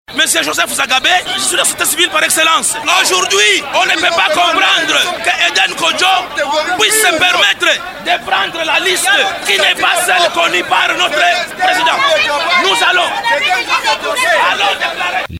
Ce dernier n’a pas reçu la réponse du facilitateur dans la cohue qui a caractérisé la fin de la cérémonie à laquelle les représentants de l’ONU, des diplomates et des acteurs politiques et sociaux de la RDC ont assisté.